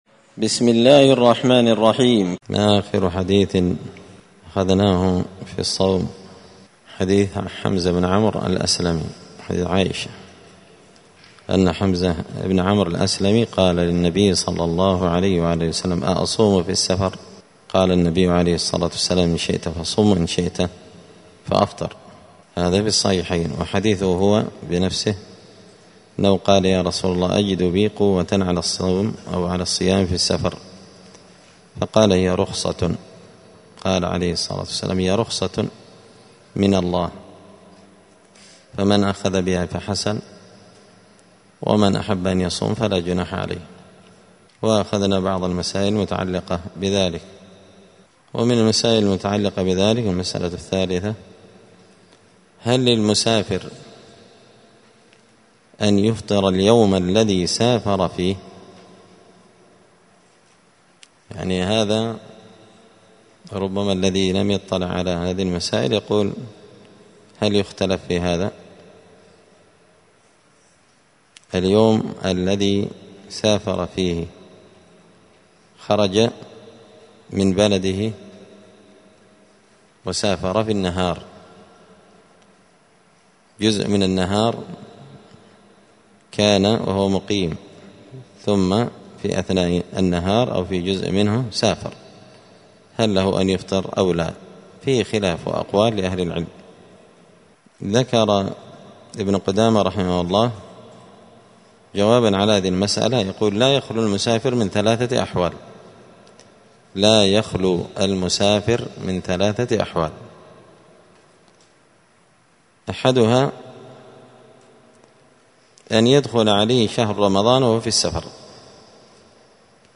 دار الحديث السلفية بمسجد الفرقان بقشن المهرة اليمن
*الدرس الثامن عشر (18) {حكم الفطر للمسافر ومازال في بيته…}*